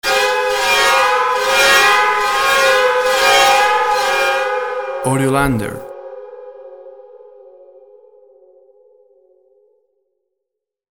WAV Sample Rate 16-Bit Stereo, 44.1 kHz